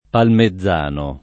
[ palme zz# no ]